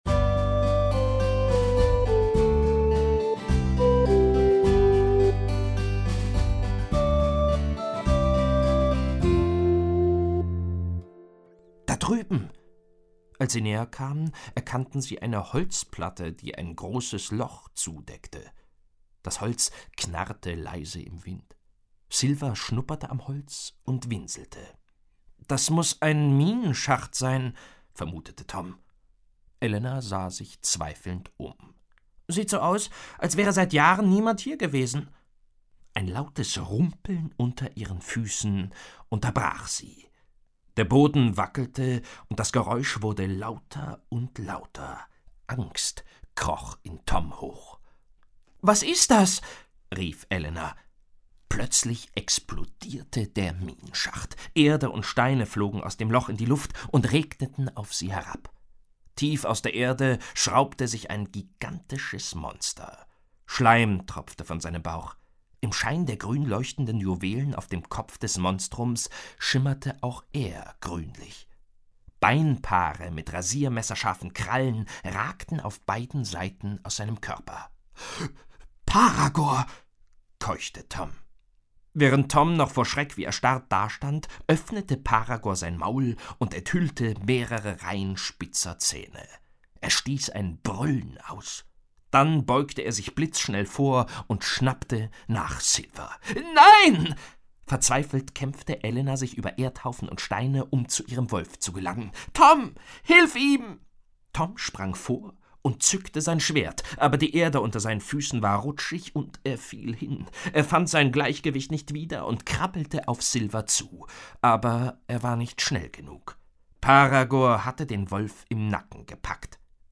Schlagworte Abenteuer • Abenteuer; Kinder-/Jugendliteratur • Fantasy • Fantasy; Kinder-/Jugendliteratur • Hörbuch; Lesung für Kinder/Jugendliche • Ritter